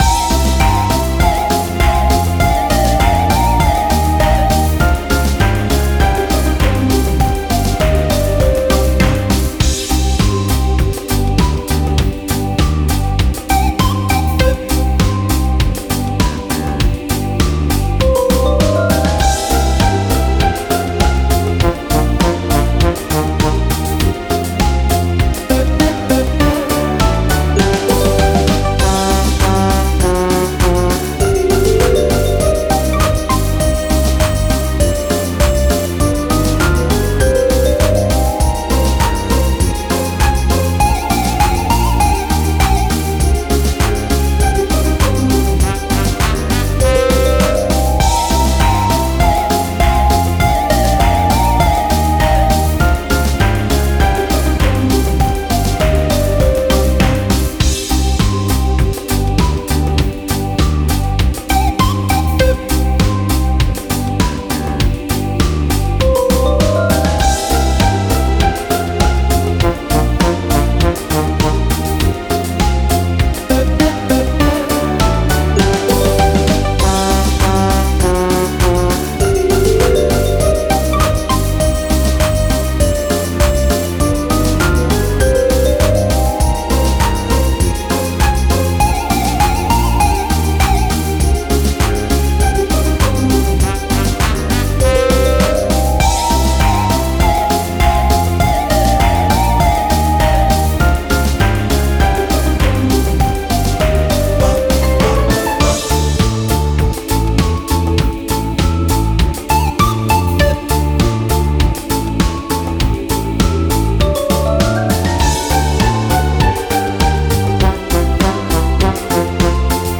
Минус песни